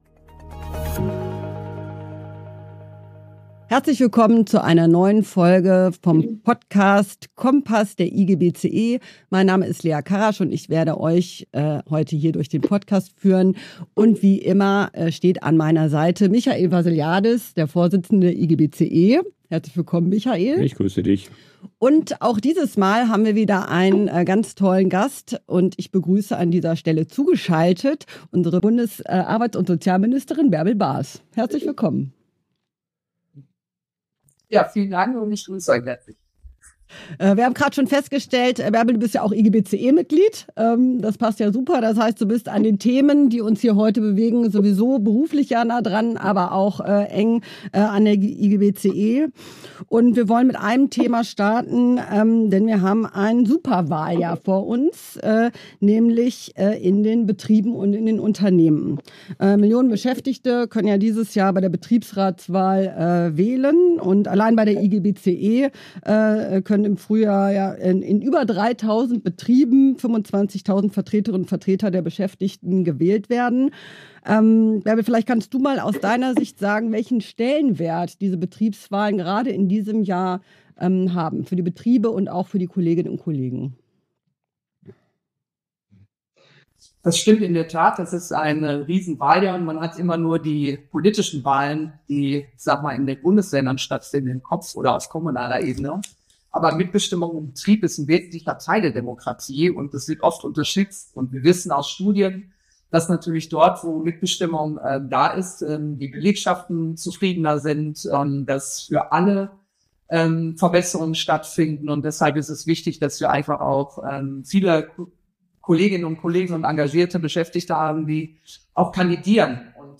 Warum ist Mitbestimmung gerade jetzt unverzichtbar? Wie ist die Lage am Arbeitsmarkt? Darüber spricht Michael Vassiliadis mit Bundesarbeitsministerin Bärbel Bas im Kompass-Talk.